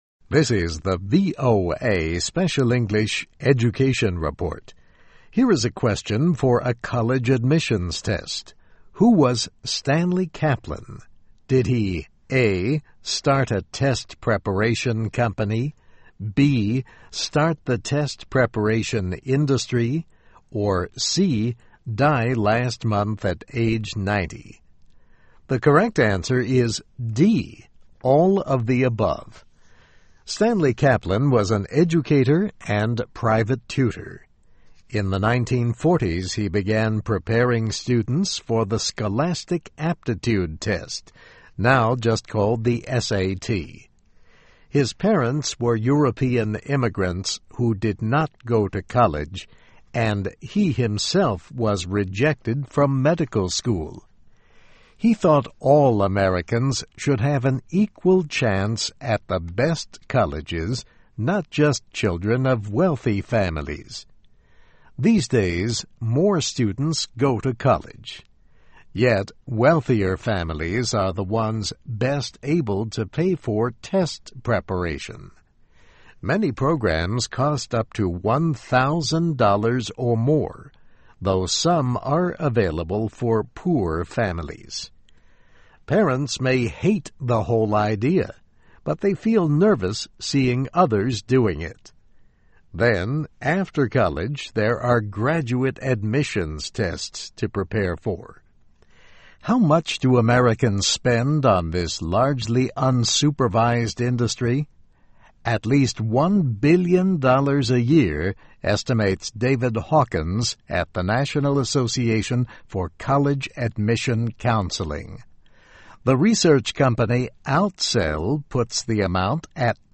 Biography: Stanley H. Kaplan (1919-2009): A Test Prep Pioneer (VOA Special English 2009-09-16)